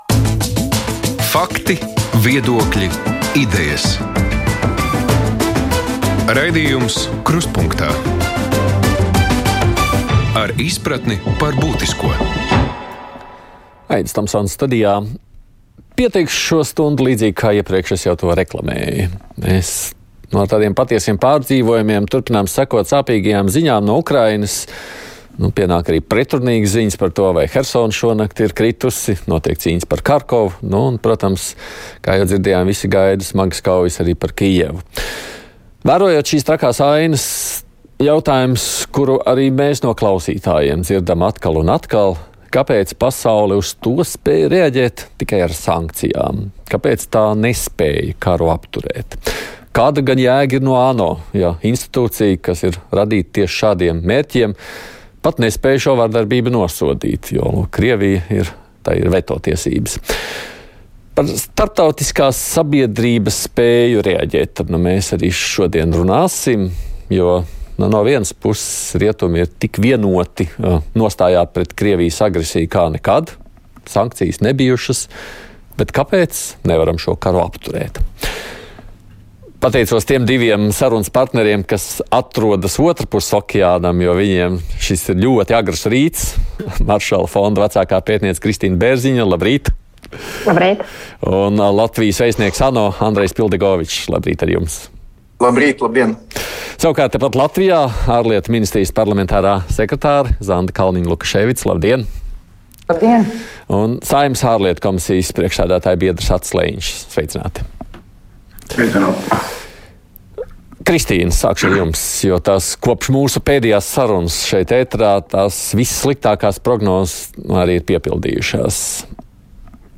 Krustpunktā diskusija. Karš Ukrainā - pasaules lielvaru spējas ietekmēt tur notiekošo.